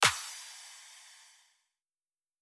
osu-logo-heartbeat.wav